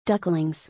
KEqkXvsSedd_ducklings.mp3